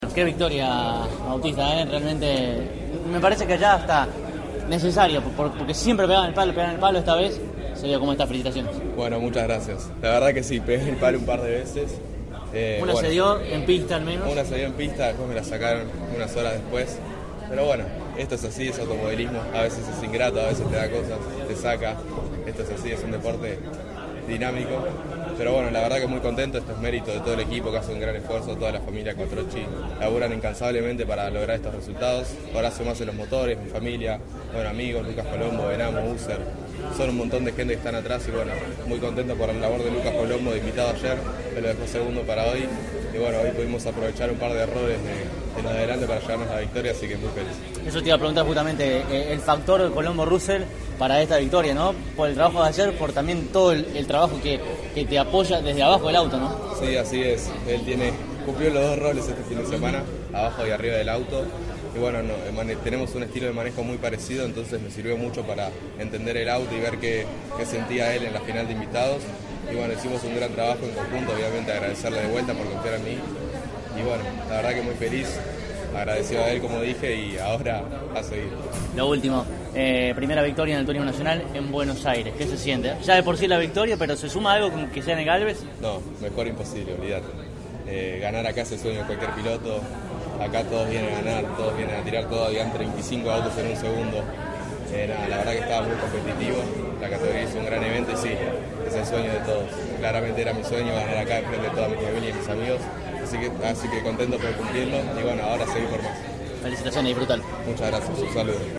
Estas son todas las entrevistas: